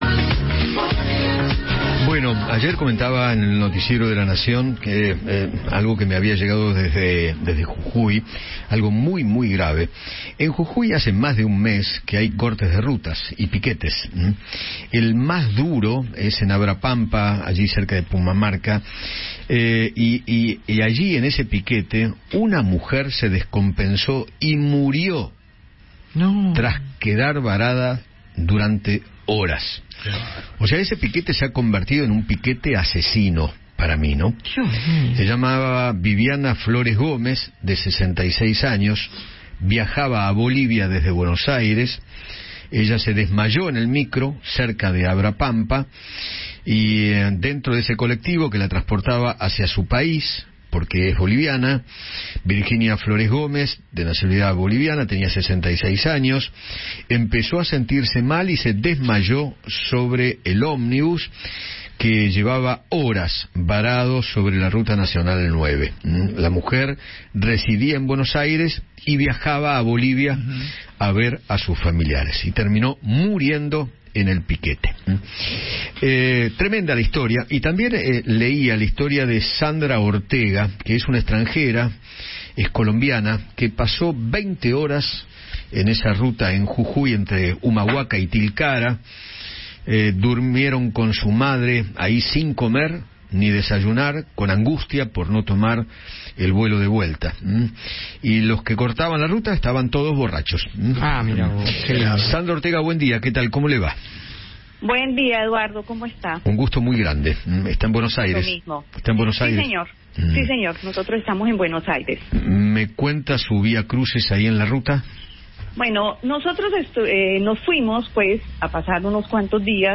dialogó con Eduardo Feinmann sobre la dramática situación que vivió con su madre mientras se trasladaba a Bolivia.